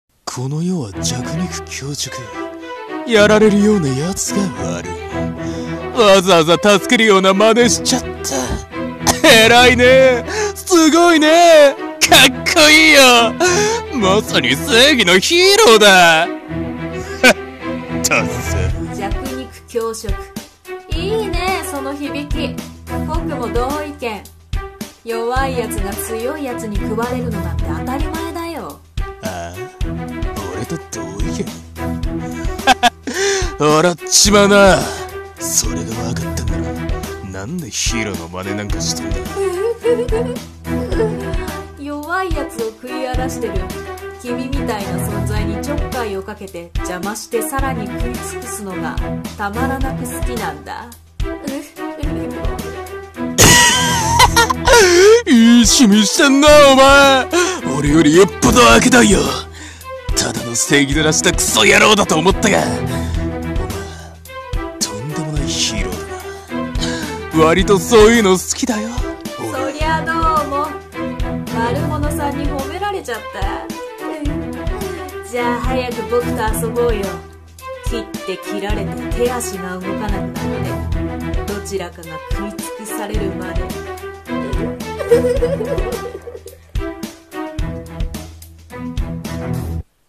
声劇/この世は弱肉強食